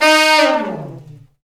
Index of /90_sSampleCDs/Roland LCDP06 Brass Sections/BRS_Section FX/BRS_Fat Falls